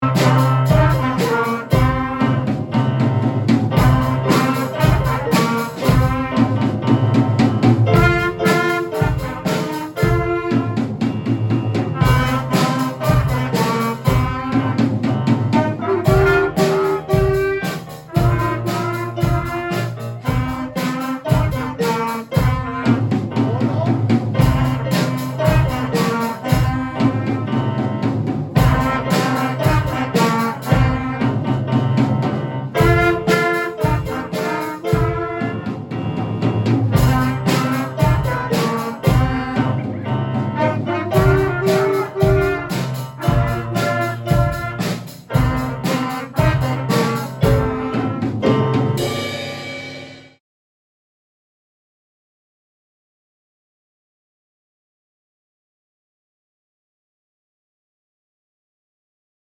Im Bandprojekt erlernen die Schülerinnen und Schüler ein Holz-, Blechblasinstrument, E-Bass oder Schlagzeug/Cajon und spielen wöchentlich in einer Band zusammen.